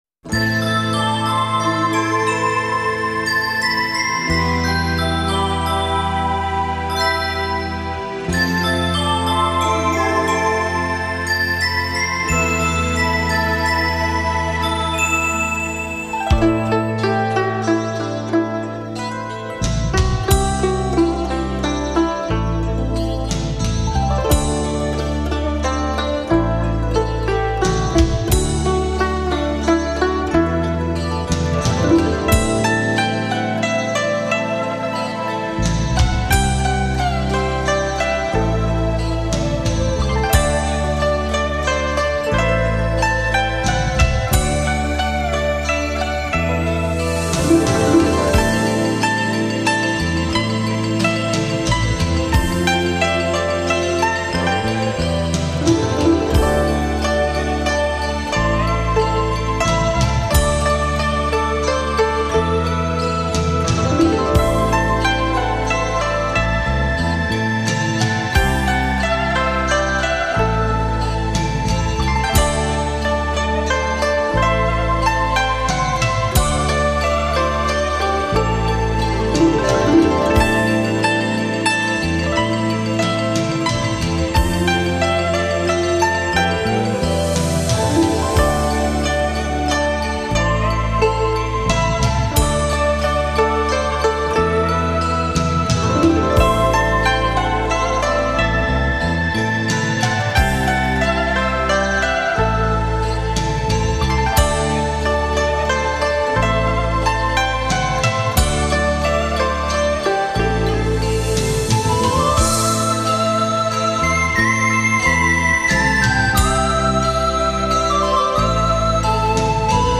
等一首首熟悉的浒音乐以美妙的旋律抒发了情感，动人的琴声，
古筝浪漫情怀让您的生活更加的精彩！